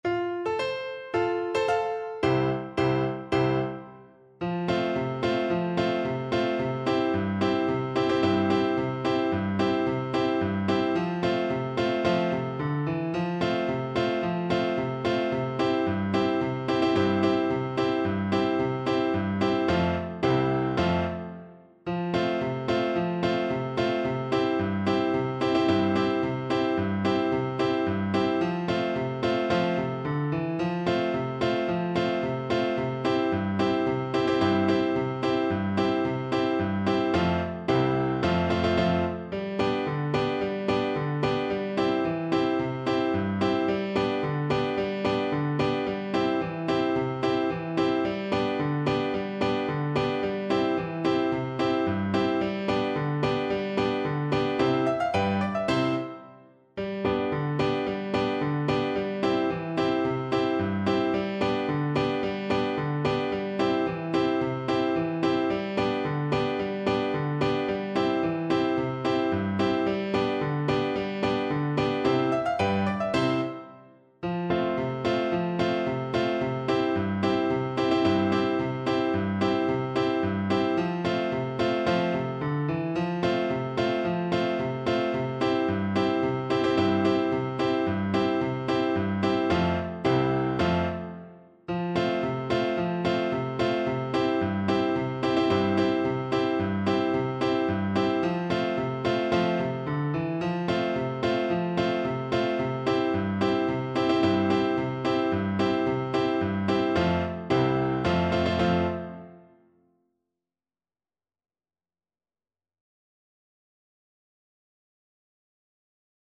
2/4 (View more 2/4 Music)
Allegro =c.110 (View more music marked Allegro)
C4-Bb5
Traditional (View more Traditional French Horn Music)